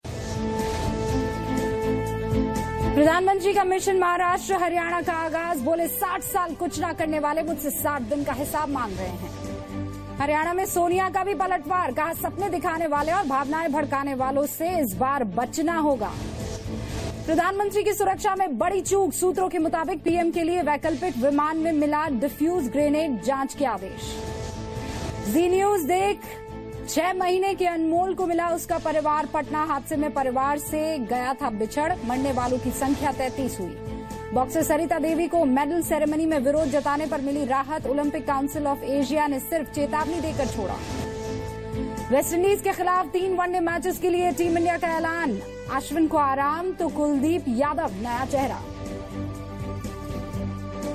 Headlines at 5 pm